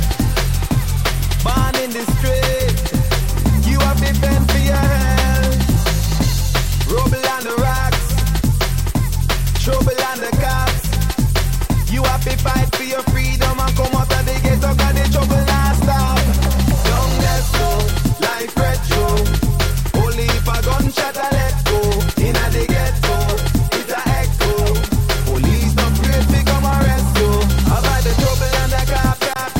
TOP >Vinyl >Drum & Bass / Jungle
TOP > Vocal Track